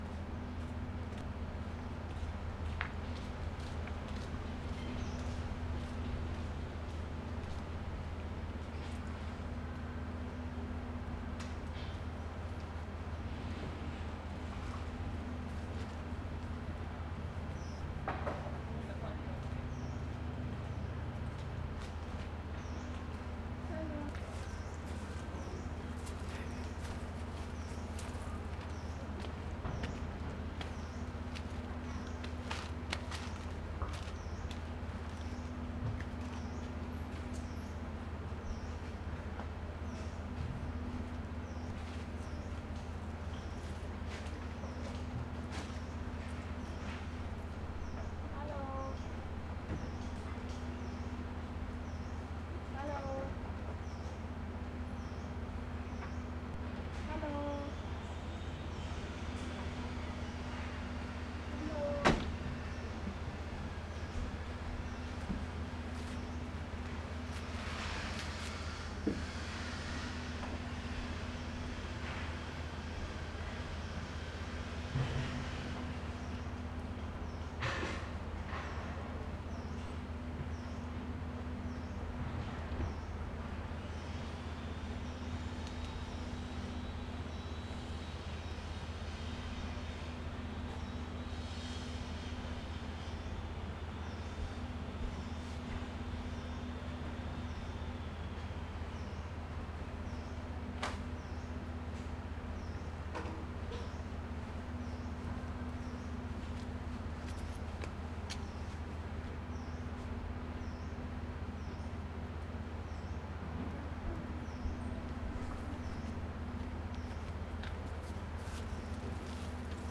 2019-12-24 14 + 16 uhr Spinnennetz (Original Video Ton).flac
19. Dezember 2019 - 6. Januar 2020 Bangkok - Koh Tao (Fieldrecording Audio, Foto, Video)
Original Video Ton: aufgenommen durch Abspielen der Videos im VLC Player und Aufnehmen mit Audacity (16Bit/44kHz)